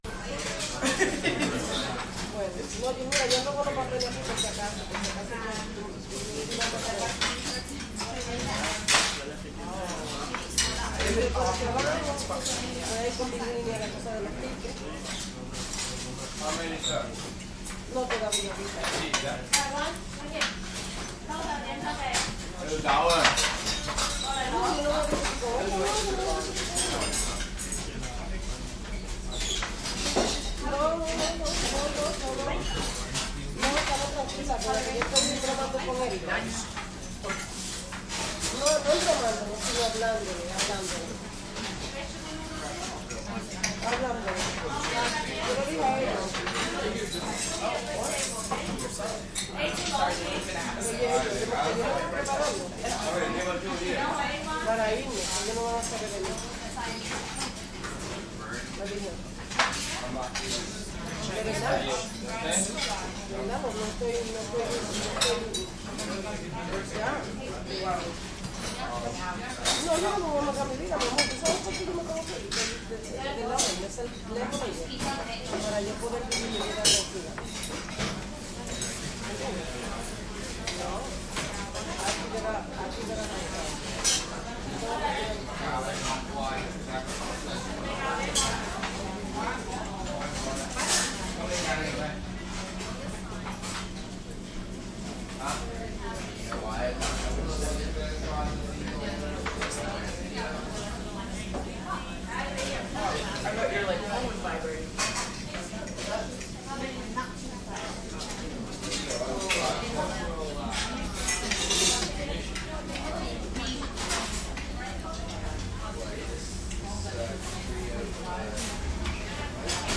蒙特利尔 " 人群中的小光瓦拉中国餐馆1 蒙特利尔，加拿大
描述：人群int小光walla中国restaurant1蒙特利尔，Canada.flac
Tag: 重量轻 INT 餐厅 中国人 人群中 沃拉